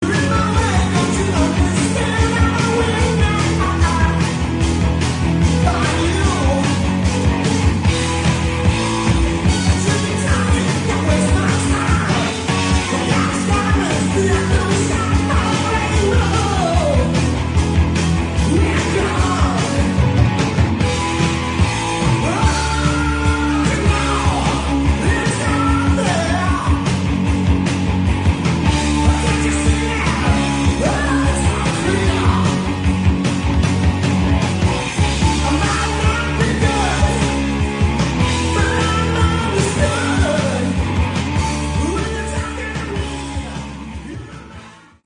Category: Hard Rock
vocals, guitar
bass
drums